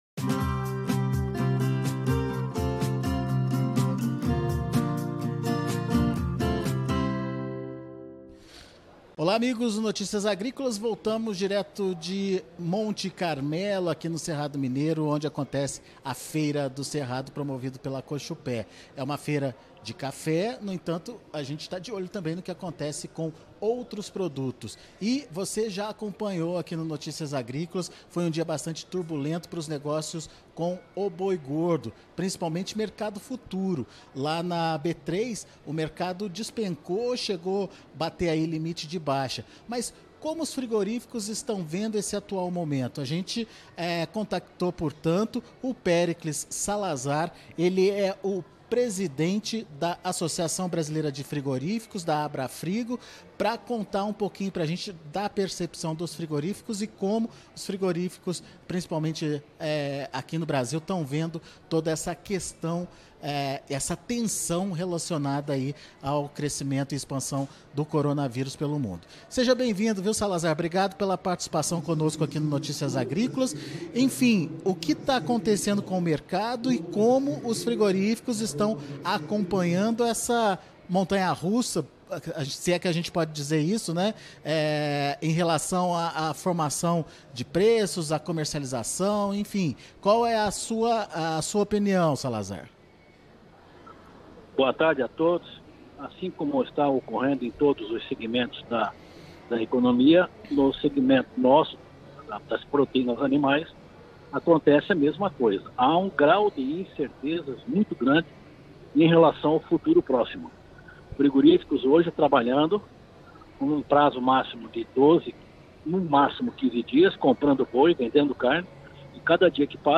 Mercado do boi gordo - Entrevista